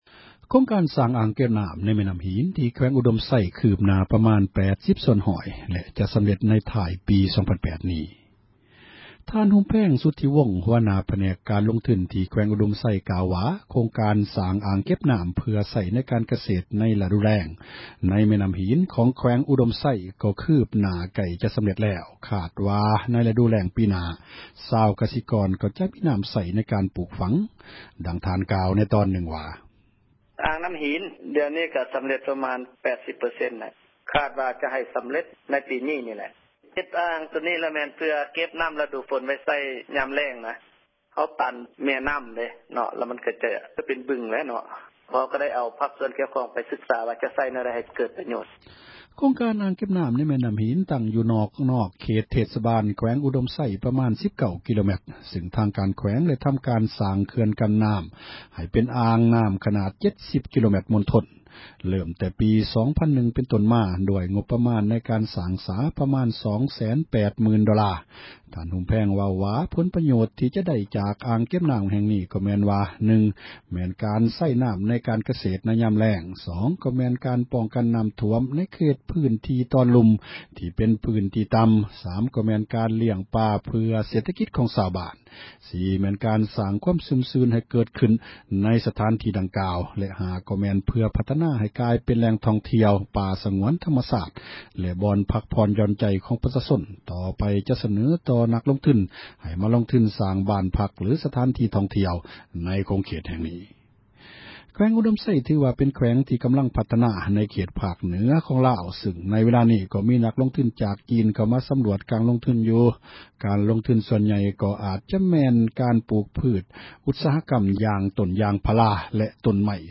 ສຽງອ່າງເກັບນ້ຳຫີນ